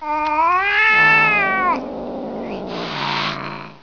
catyowl.wav